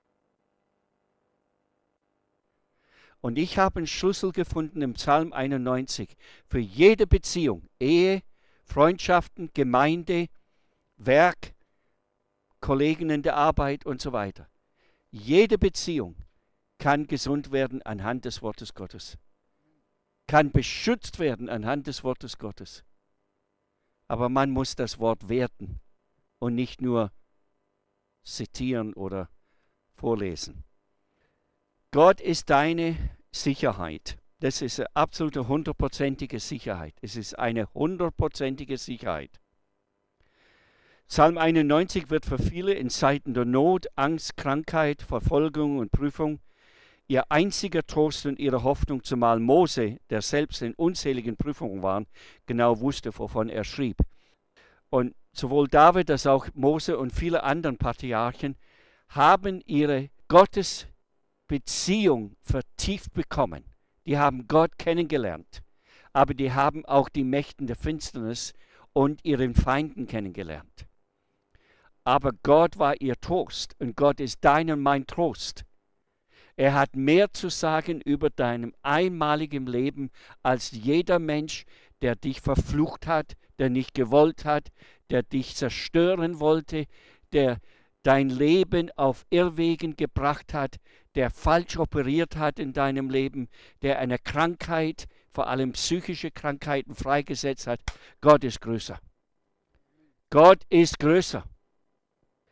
Referent